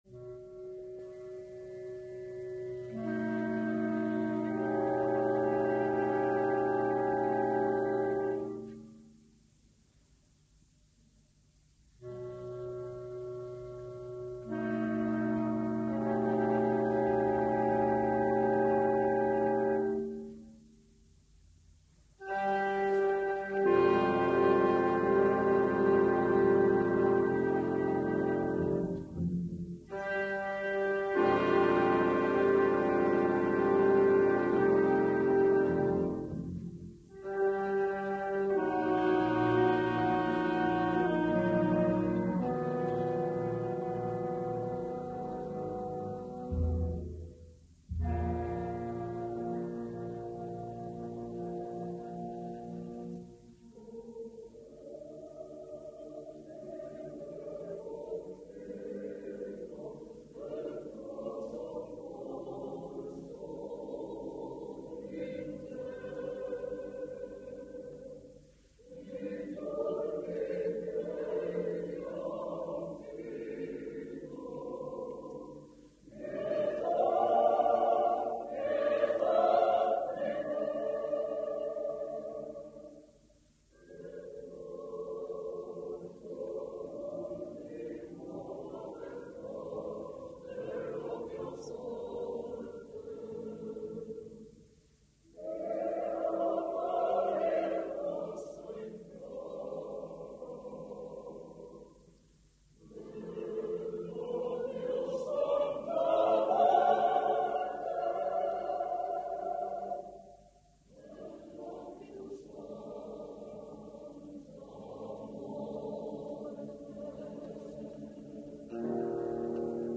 Revolutionsoper — {historischer Verismo}